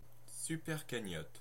Ääntäminen
Ääntäminen France (Normandie): IPA: /sy.pɛʁ.ka.ɲɔt/ Haettu sana löytyi näillä lähdekielillä: ranska Käännöksiä ei löytynyt valitulle kohdekielelle.